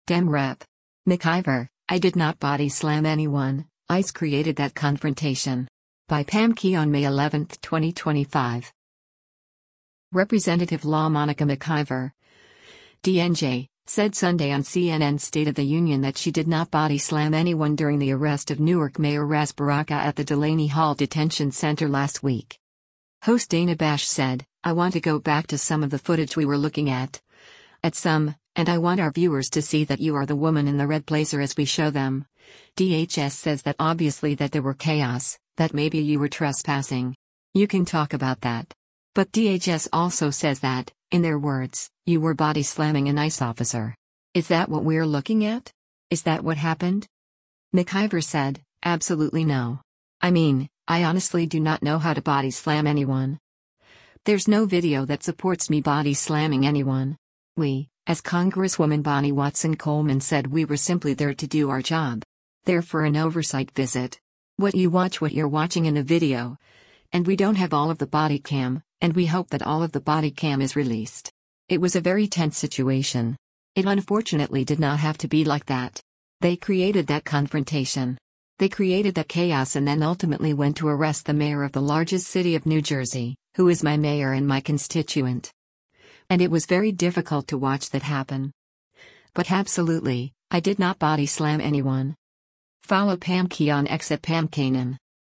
Representative LaMonica McIver (D-NJ) said Sunday on CNN’s “State of the Union” that she did not body slam anyone during the arrest of Newark Mayor Ras Baraka at the Delaney Hall Detention Center last week.